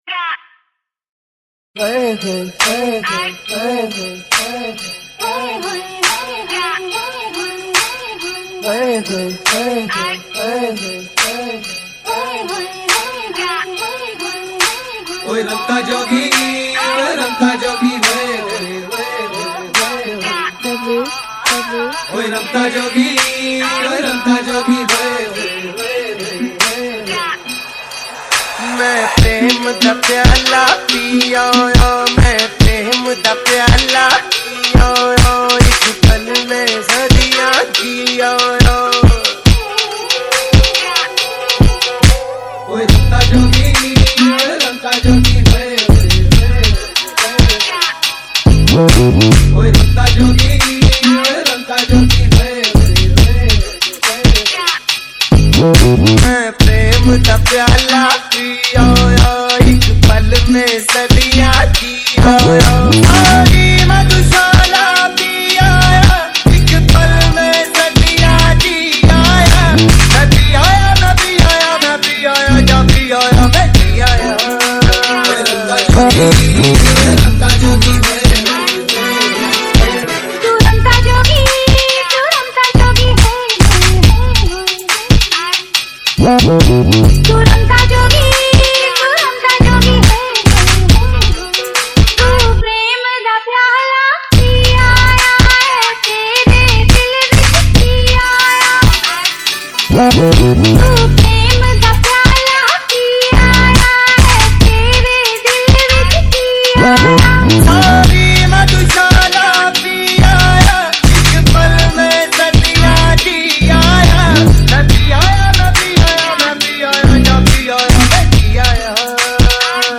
Hindi Dj Remix songs